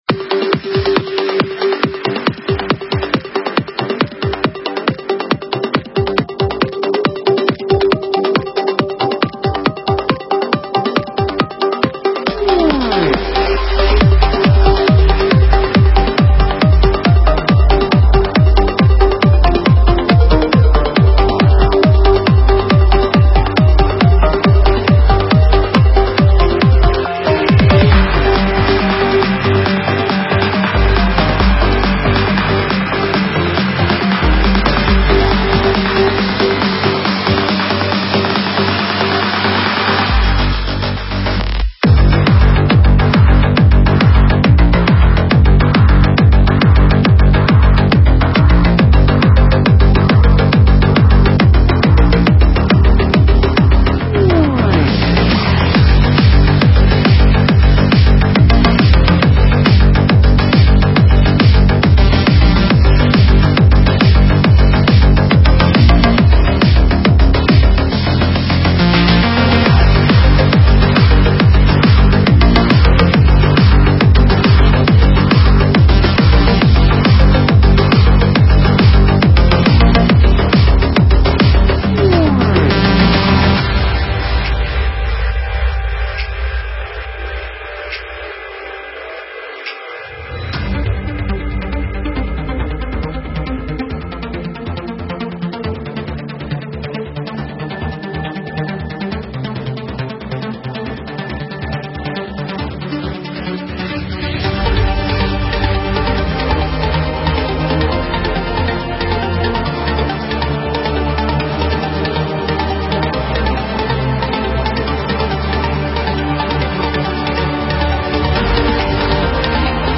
Стиль: Trance